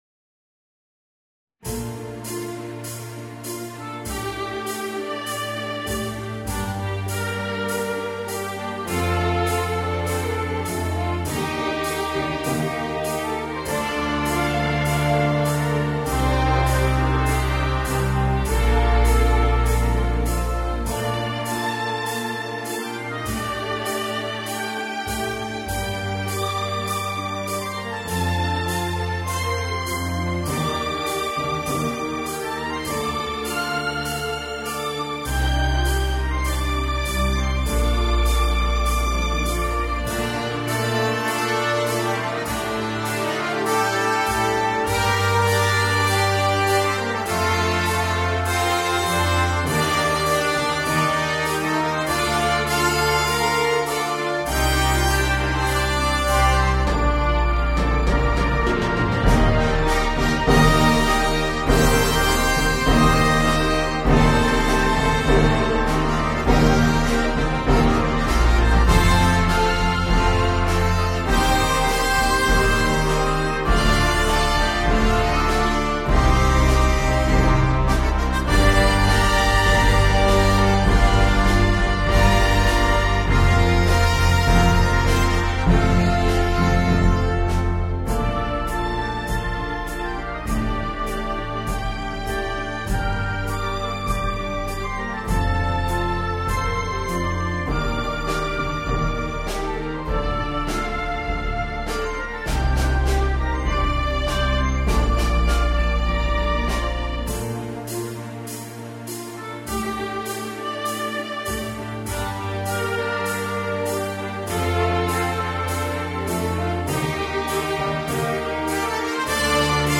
The MP3 was recorded with NotePerformer 3.